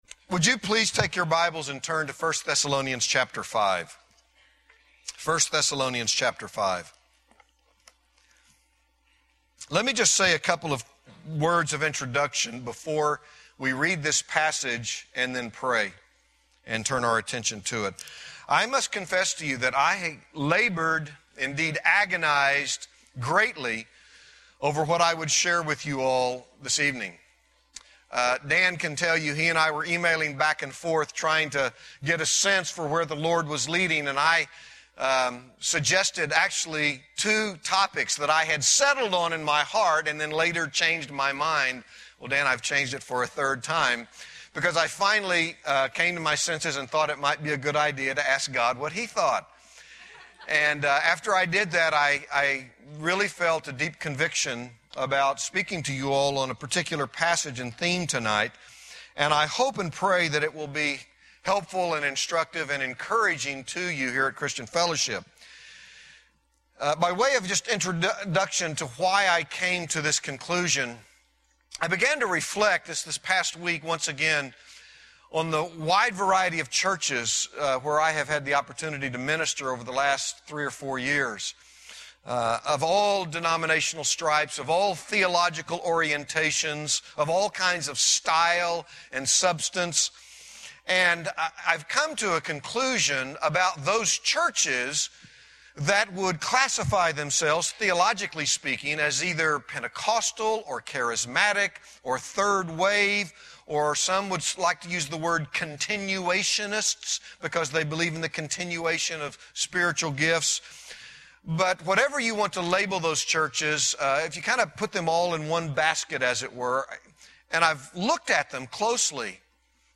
In this sermon, the speaker begins by confessing that he struggled to decide on a topic to share with the audience.